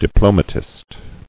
[di·plo·ma·tist]